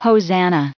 added pronounciation and merriam webster audio
1591_hosanna.ogg